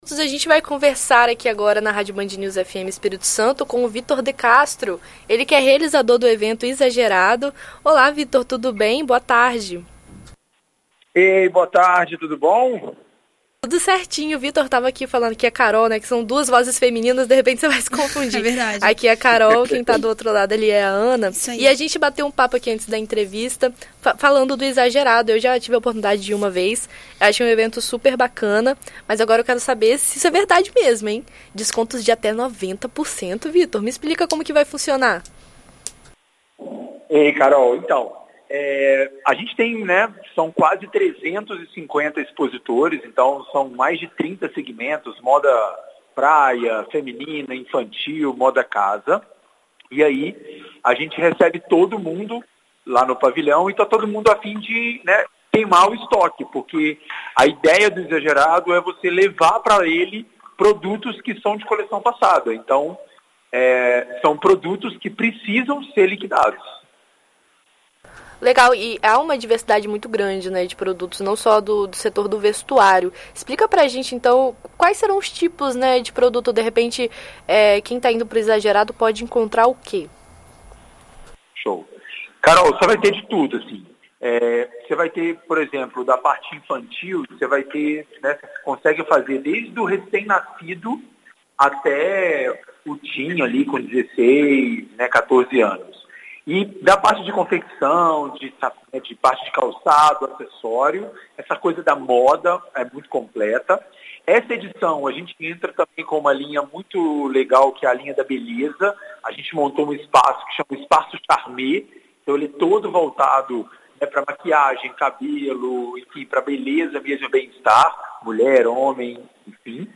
Em entrevista à BandNews FM ES